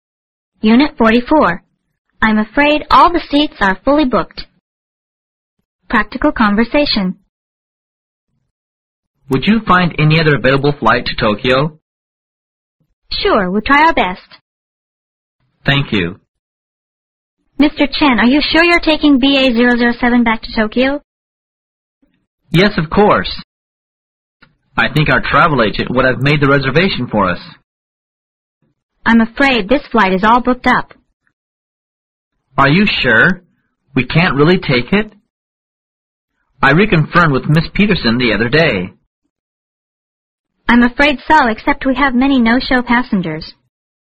Practical Conversation